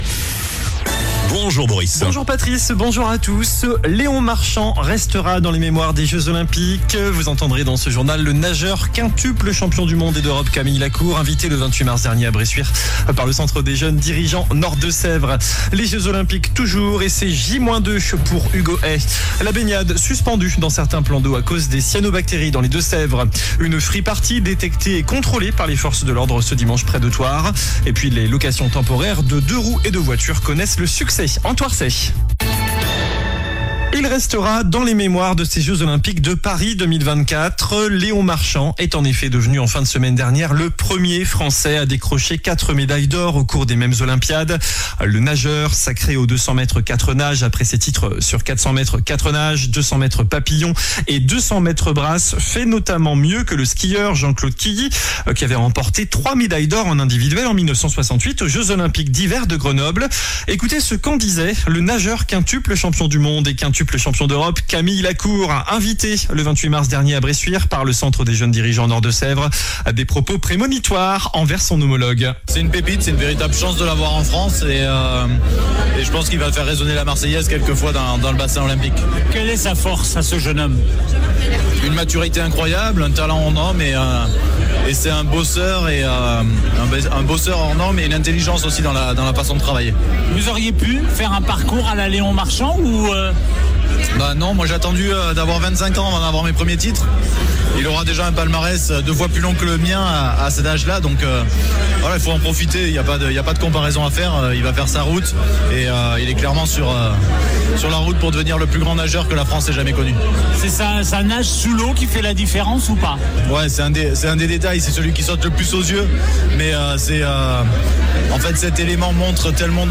JOURNAL DU LUNDI 05 AOÛT ( MIDI )